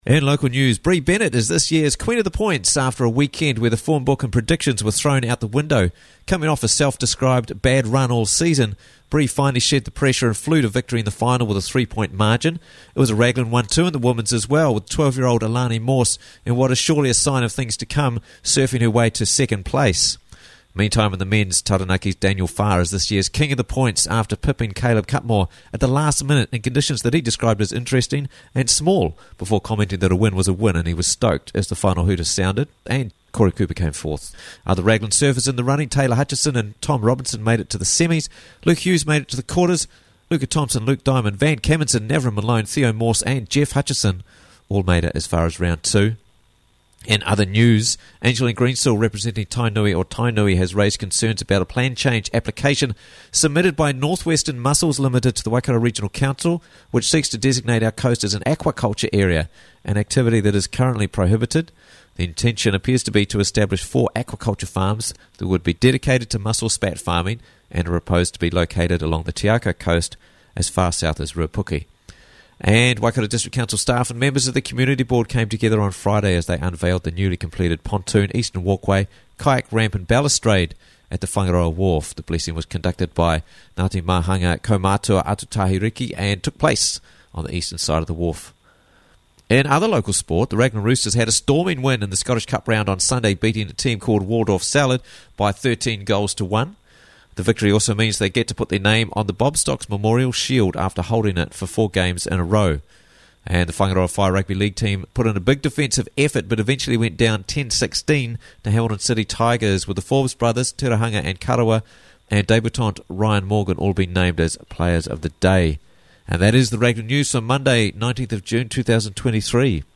Raglan News Monday 19th June 2023 - Raglan News Bulletin